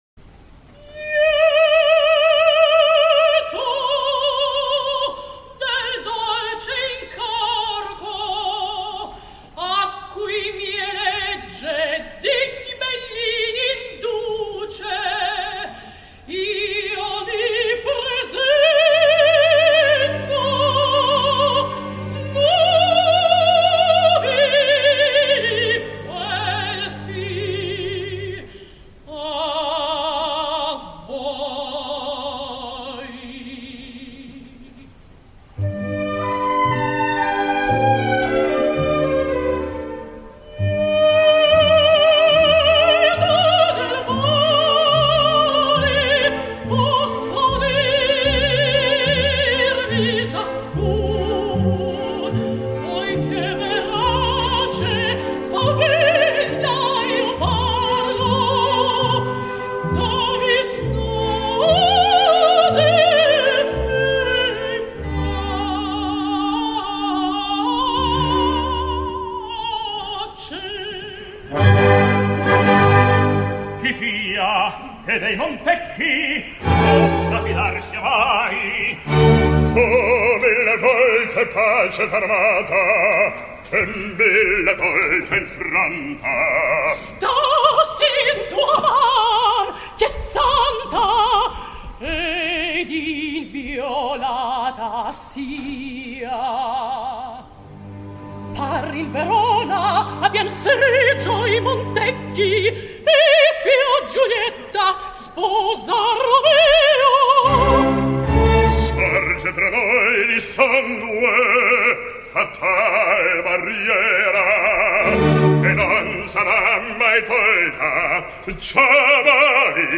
Us proposo la versió original per a mezzosoprano, ja que com descobrirem en l’apunt preparatori, també hi ha la versió per a tenor, que en aquesta tria obviaré.